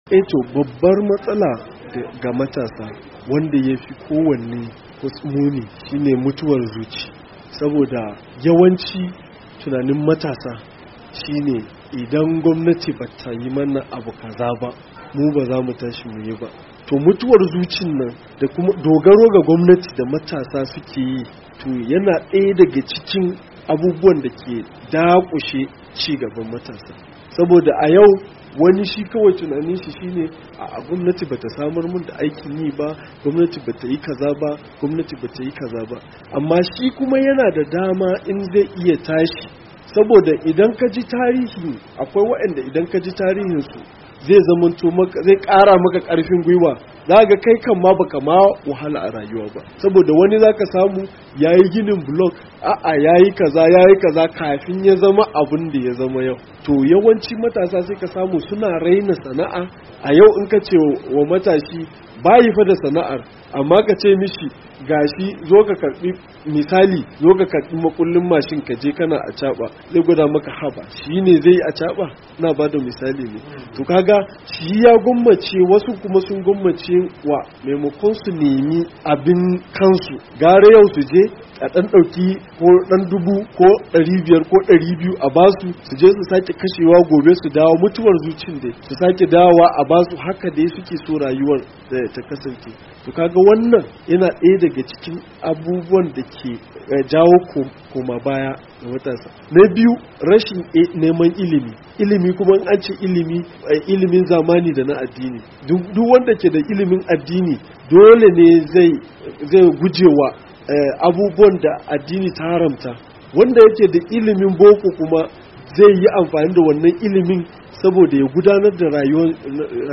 A cikin hirarsa da Dandalin VOA